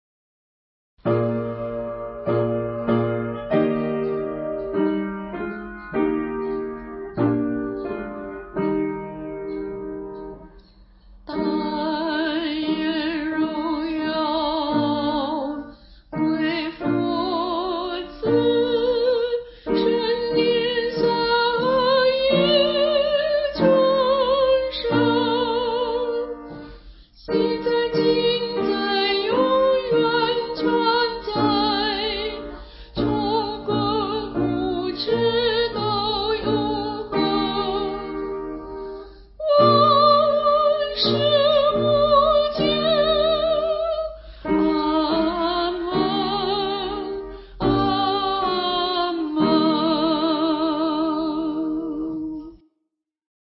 261伴奏
曲调庄严和谐，足令参加聚会的人肃然起敬。